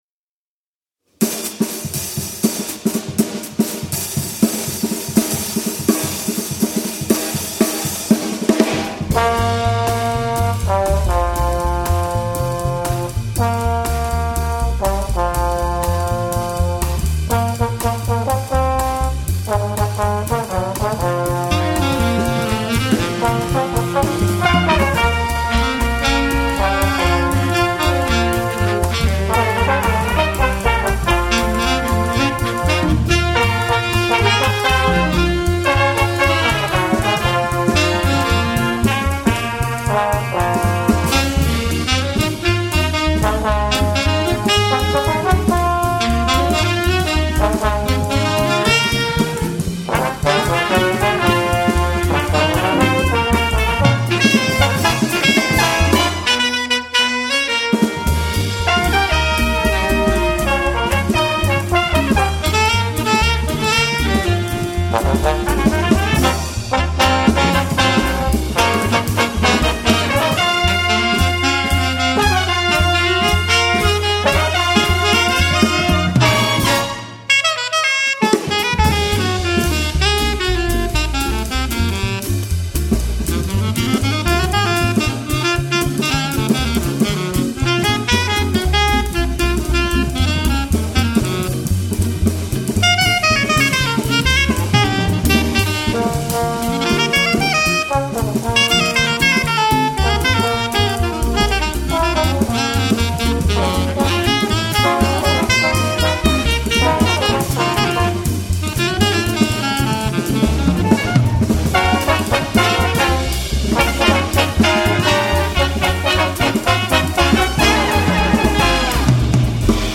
Jazz Duo / Jazz Trio and Big Band with Vocals